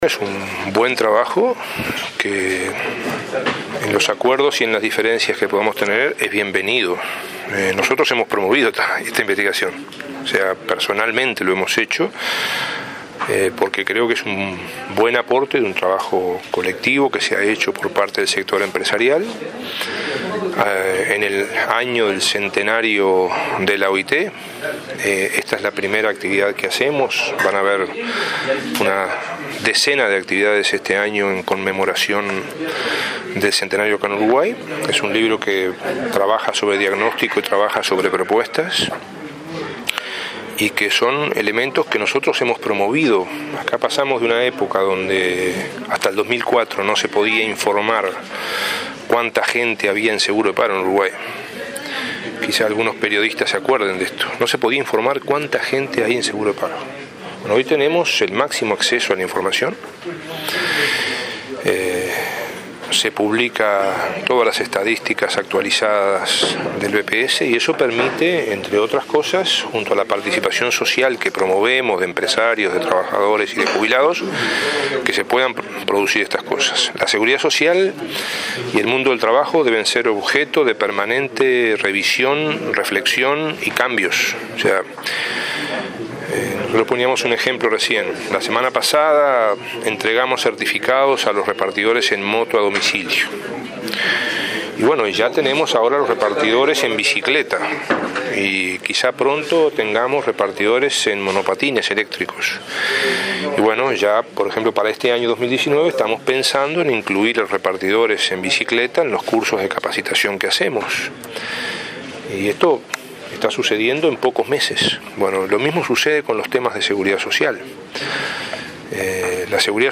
La representación empresarial del BPS presentó el libro “El Futuro del Trabajo y su impacto en la Seguridad Social”. En este marco, el ministro Ernesto Murro habló de los cambios en el mercado laboral. Recordó que en 2004 la seguridad social requería cinco veces más asistencia del Estado que en la actualidad.